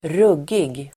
Uttal: [²r'ug:ig]